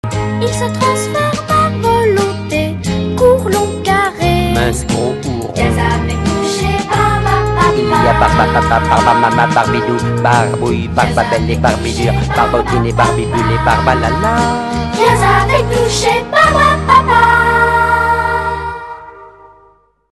*** Break musical ***